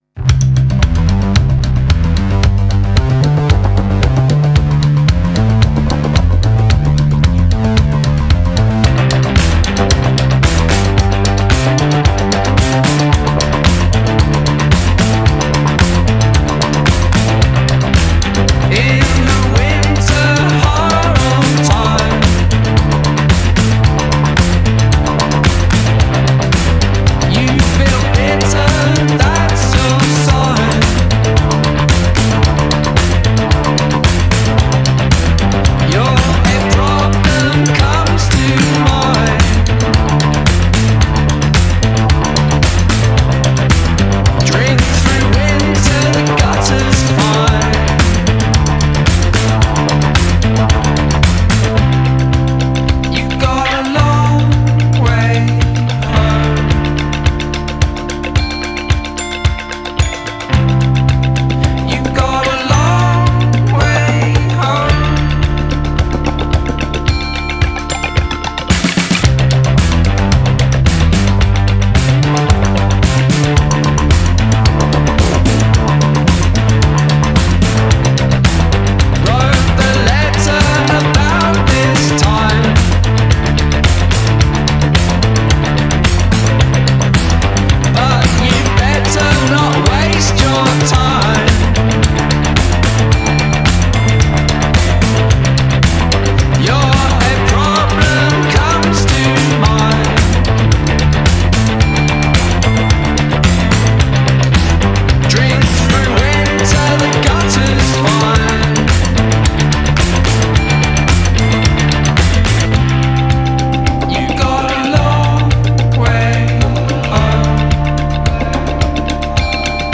Indie Rock, MP3, Pop, Post-Punk, Video